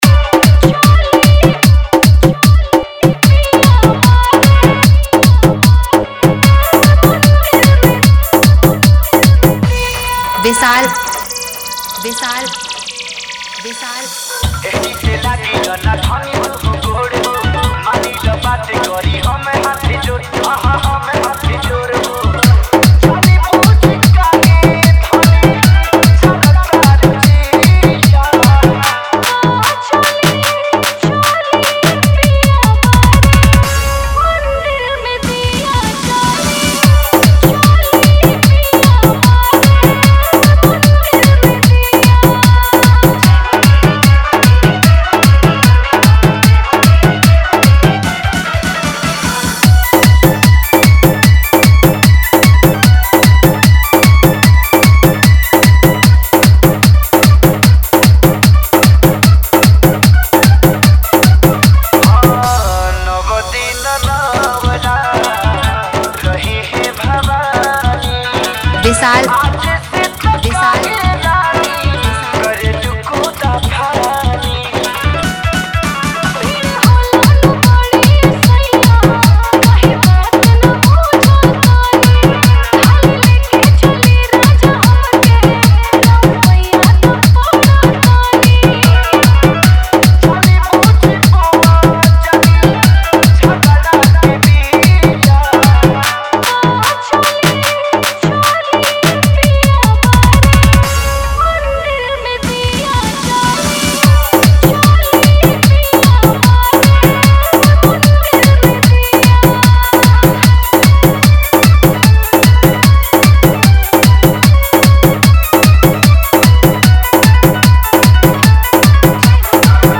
All Bhakti Dj Remix Songs